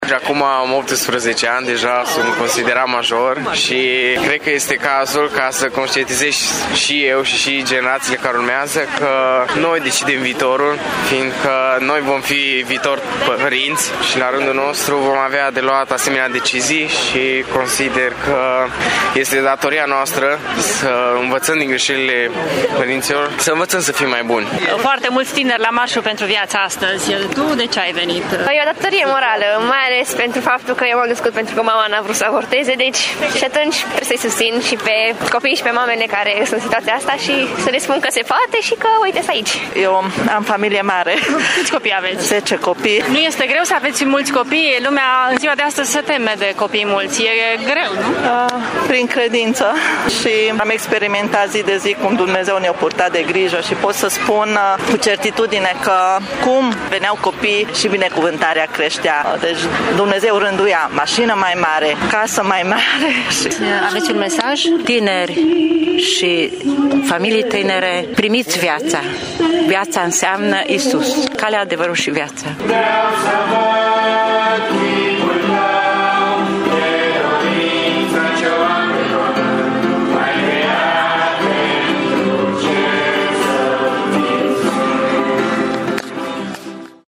Anul acesta, mai mult de jumătate dintre participanții de la Tîrgu-Mureș au fost tineri. Aceștia spun că au o datorie morală pentru a îndrepta greșelile părinților lor: